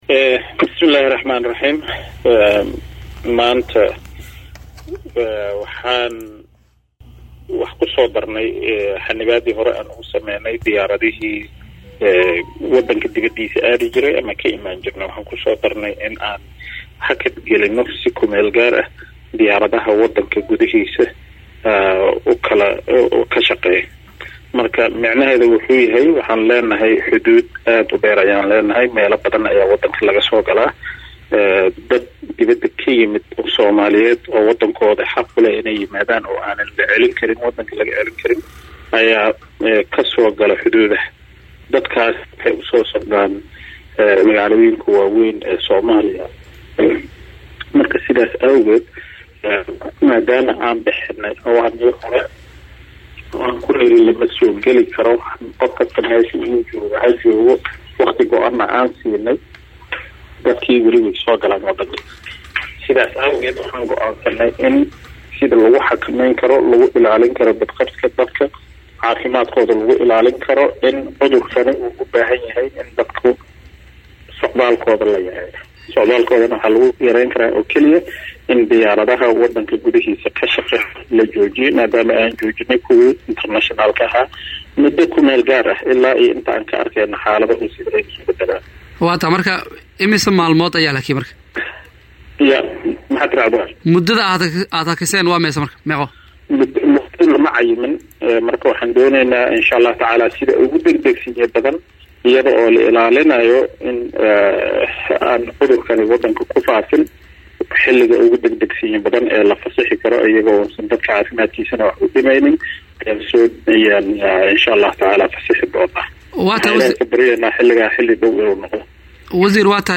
Wasiirka wasaaradda Gaadiidka iyo Duulista Hawada Xukuumadda Federaalka Soomaaliya mudane Maxamed Cabdullaahi Salaad (Oomaar) oo wareysi siiyay Radio Muqdisho Codka Jamhuuriyadda Soomaaliya ayaa faahfaahin ka bixiyay hakinta duulimaadyada gudaha dalka oo si KMG ah dowladu u joojisay laga bilaabo maalinta berri ah.
Halkaan Hoose ka dhageyso Wareysiga Wasiirka
WAREYSI-WASIIRKA-GADIIDKA-IYO-DUULISTA-HAWADA-.mp3